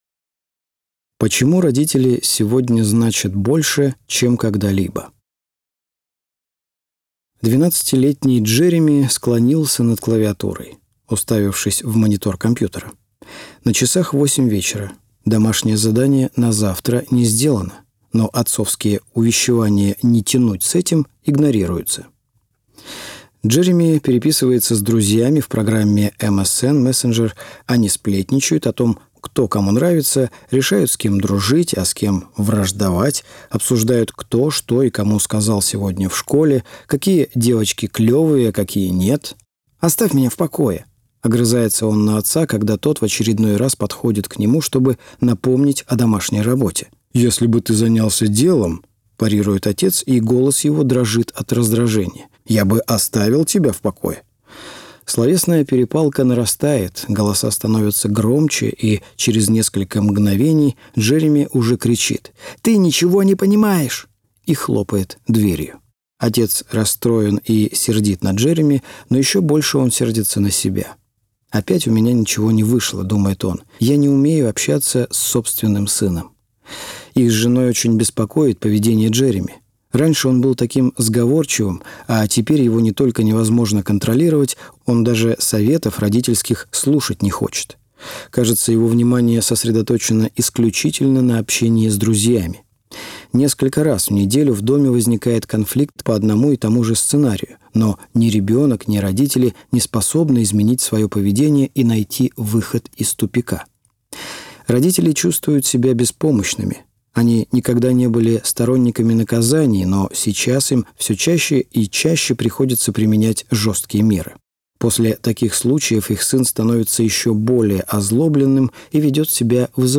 Аудиокнига Не упускайте своих детей | Библиотека аудиокниг